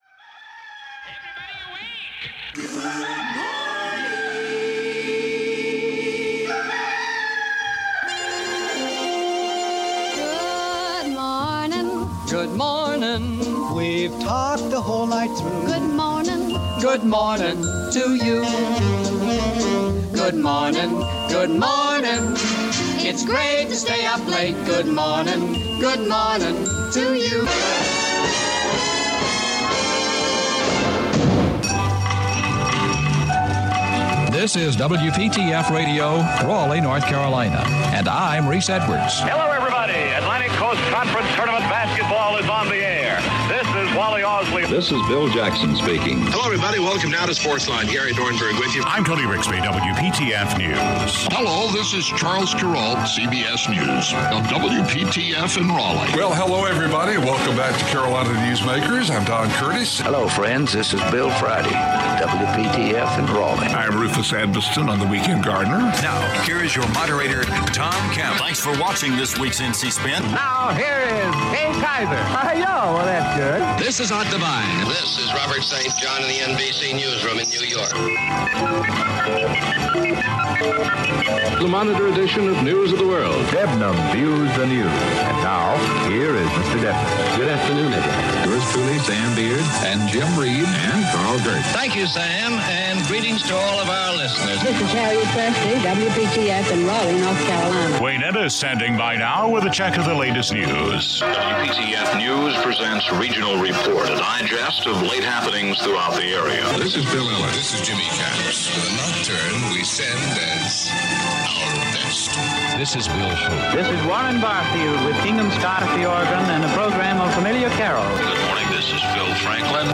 The WPTF Weekend Gardener is an award-winning radio talk show that has been on the air for over 40 years.